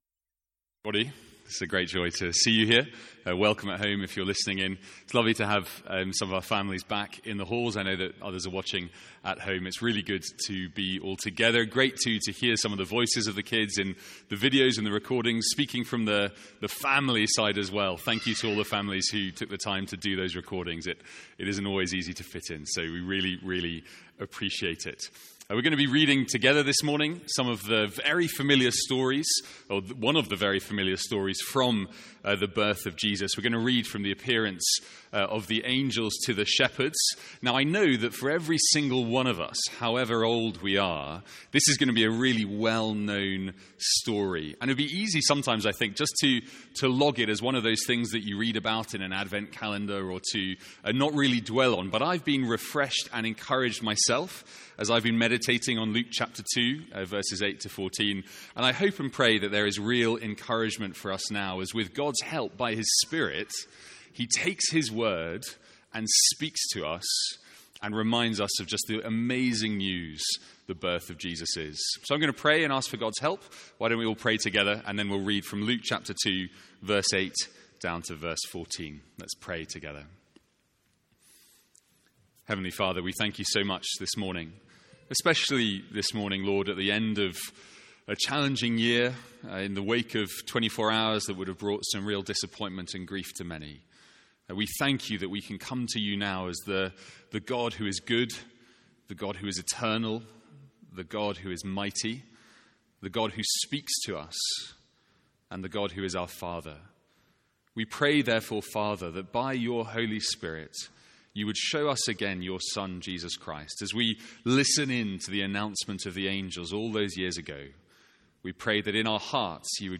All Age Carol Service
Our all age carol service.